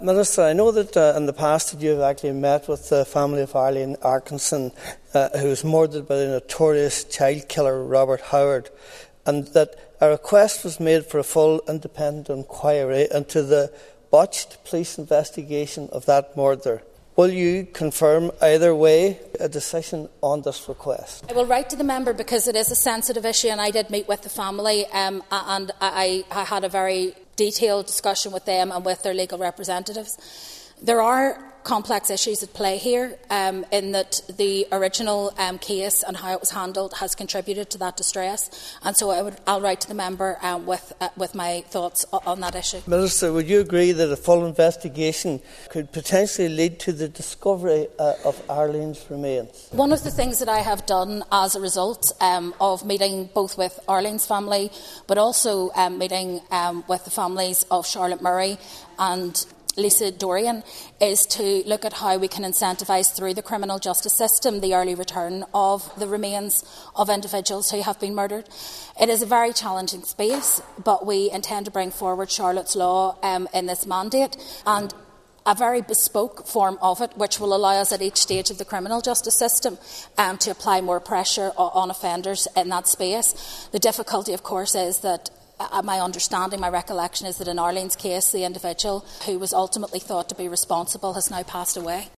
The North’s Justice Minister has told the Stormont Assembly laws will be enacted that would deny parole to people convicted of murder who haven’t disclosed where they disposed of the bodies of their victims.